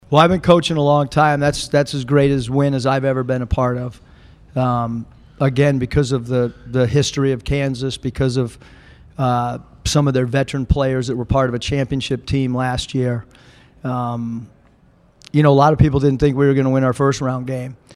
That’s Arkansas coach Eric Musselman who was shirtless after the game while leading Razorback fans in cheers.